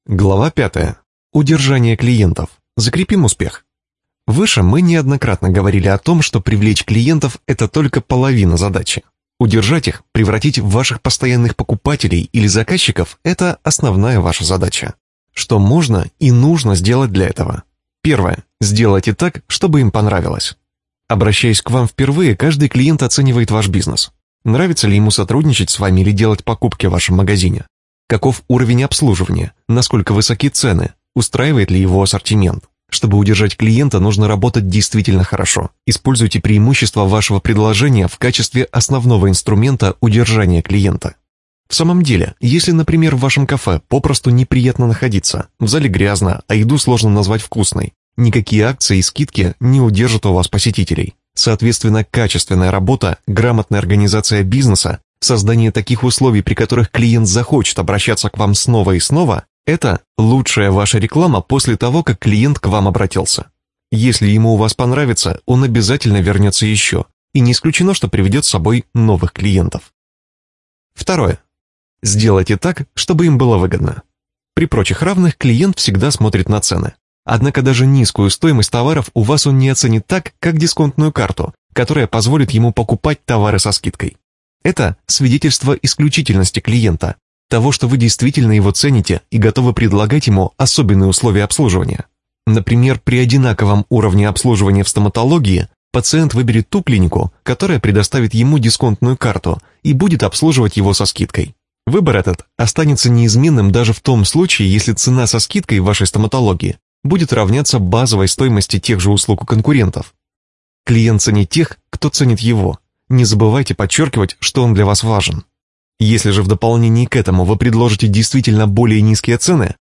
Аудиокнига Больше клиентов для вашего бизнеса!